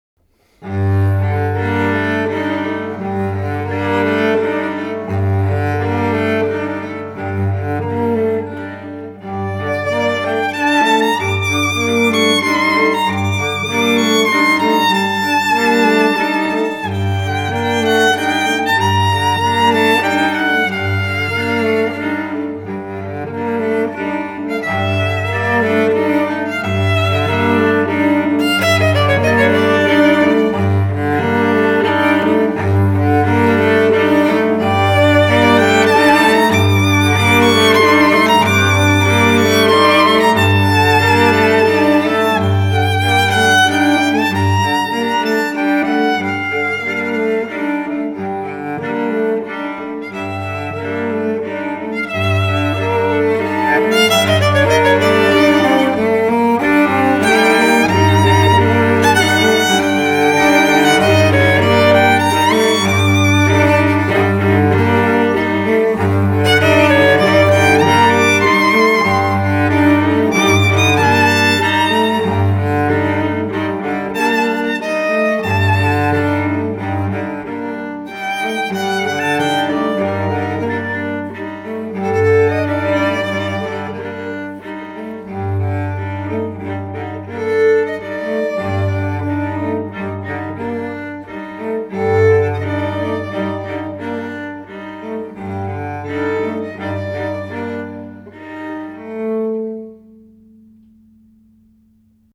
violon
alto
violoncelle
guitare, clarinette en Bb, clarinette basse, programmation